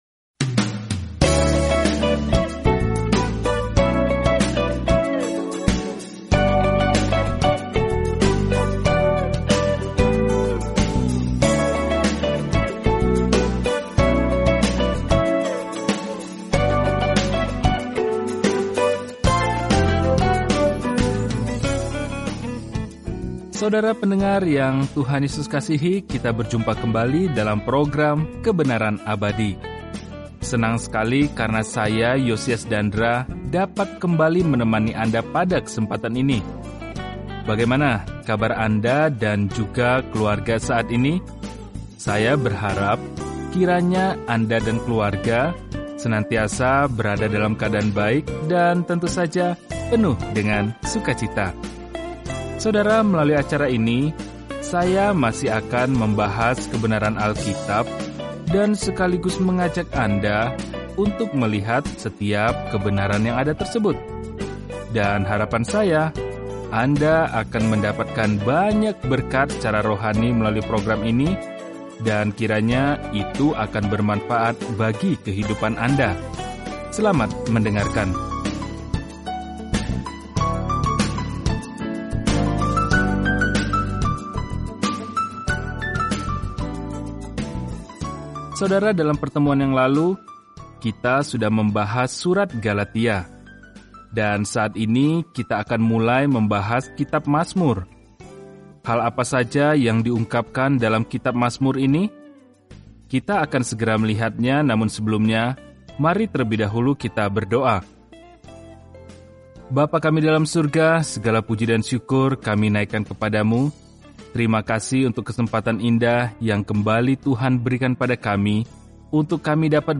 Firman Tuhan, Alkitab Mazmur 1:1 Mulai Rencana ini Hari 2 Tentang Rencana ini Mazmur memberi kita pemikiran dan perasaan tentang serangkaian pengalaman bersama Tuhan; kemungkinan masing-masing aslinya disetel ke musik. Bacalah Mazmur setiap hari sambil mendengarkan pelajaran audio dan membaca ayat-ayat tertentu dari firman Tuhan.